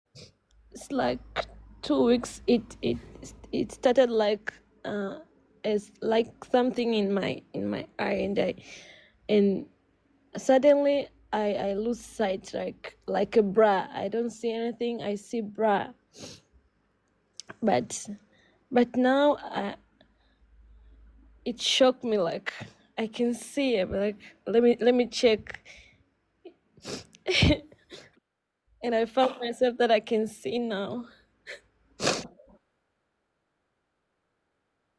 See what happened over our live video today!!!! Blind eyes opened in the name of Jesus